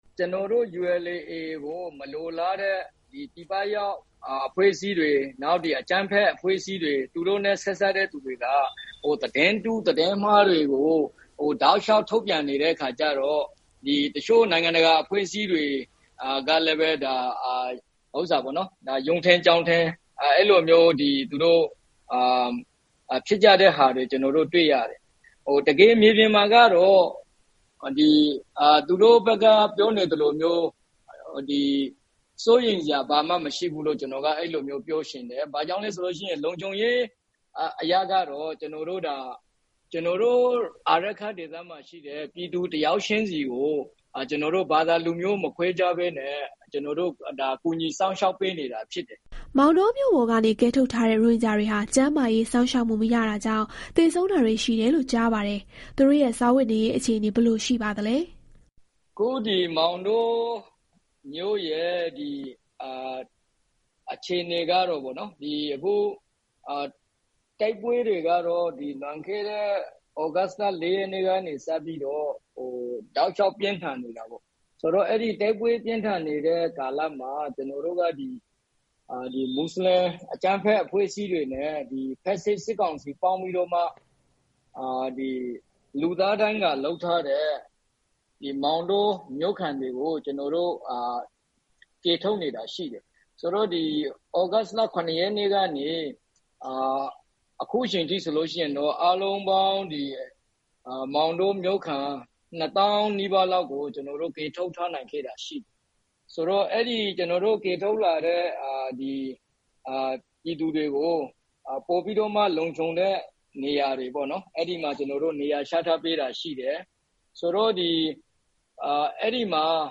လူ့အခွင့်အရေး ချိုးဖောက်မှု စွပ်စွဲချက်တွေအတွက် AA အဖွဲ့နဲ့ ဆက်သွယ်မေးမြန်းချက်